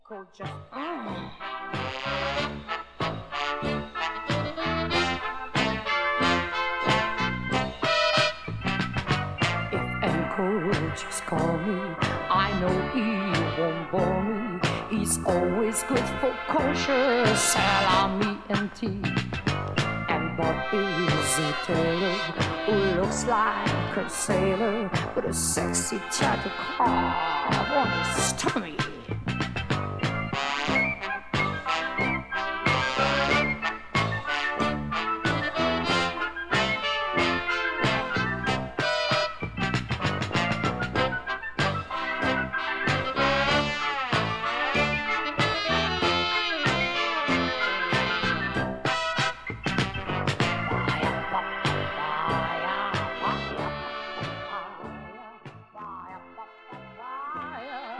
Recorded: Olympic Sound Studio in Barnes / London, England